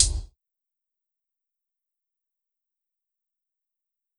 Closed Hat (Couch).wav